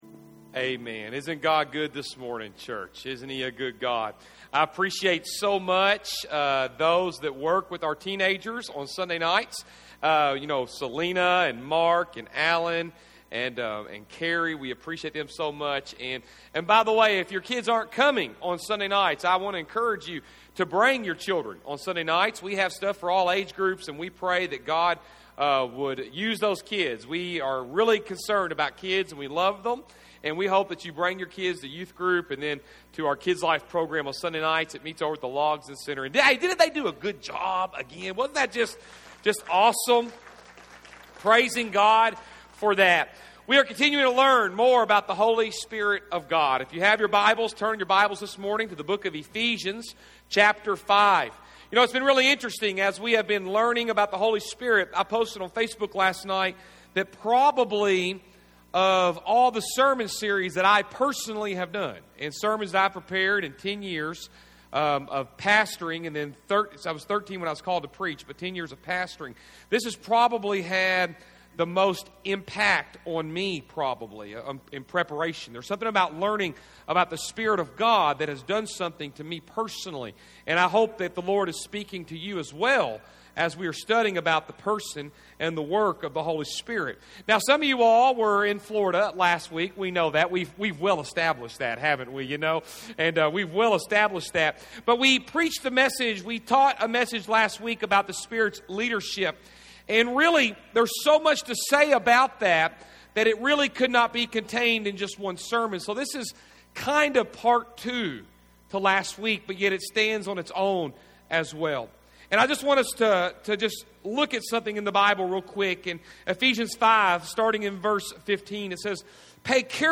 October 18, 2015 Movement: “The Spirit’s Control” Service Type: Sunday AM | 5th message in the series “Movement” on The Spirit’s Control.